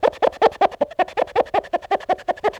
cartoon_squeaky_cleaning_loop_01.wav